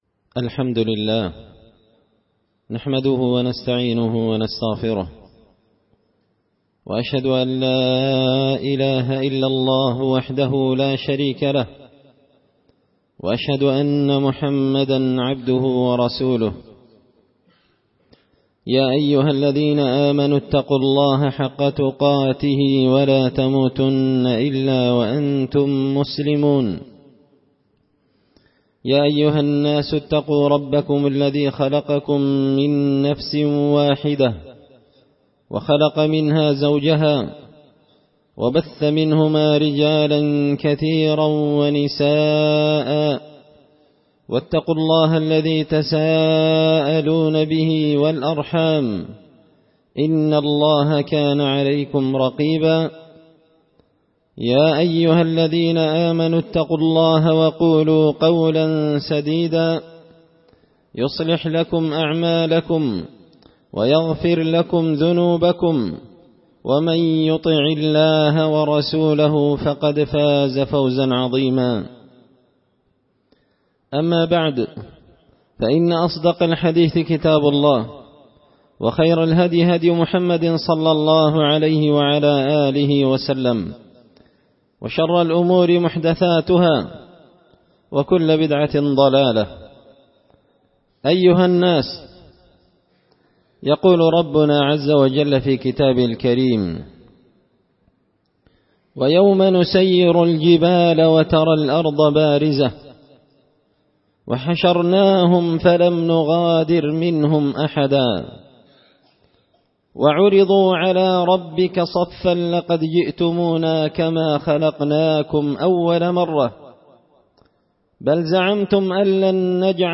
خطبة جمعة بعنوان – لقد جئتمونا كما خلقنا كم أول مرة
دار الحديث بمسجد الفرقان ـ قشن ـ المهرة ـ اليمن